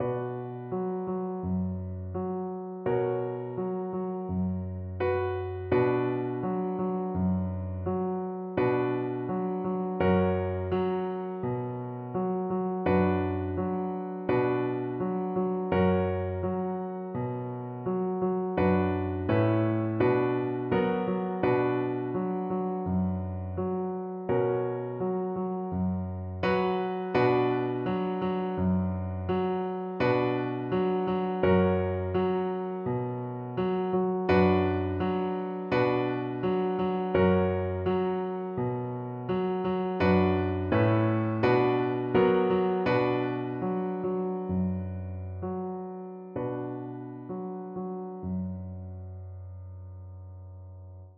Christmas Christmas Violin Sheet Music Pat-a-Pan
Violin
2/2 (View more 2/2 Music)
B minor (Sounding Pitch) (View more B minor Music for Violin )
Steady two in a bar ( = c. 84)
Traditional (View more Traditional Violin Music)